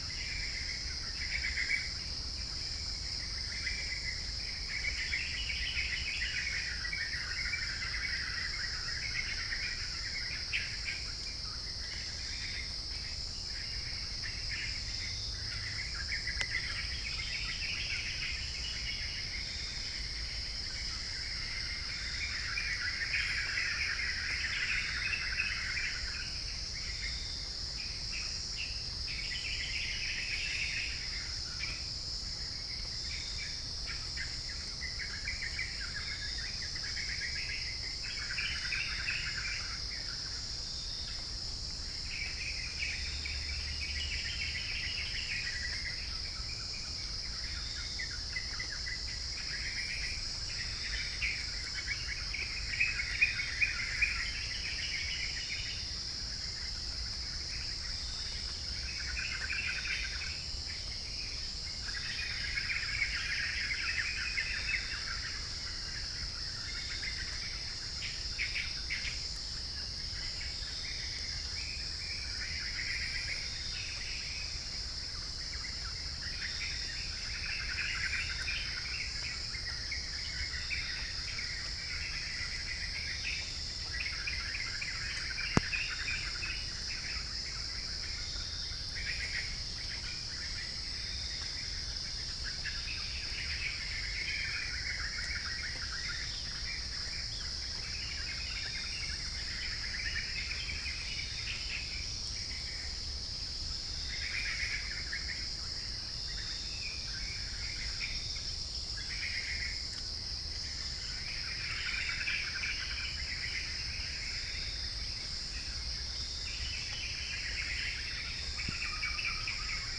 12091 | Prinia familiaris 13026 | Prinia familiaris 12090 | Pycnonotus goiavier 12092 | Arachnothera hypogrammica